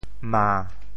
潮州发音 潮州 ma7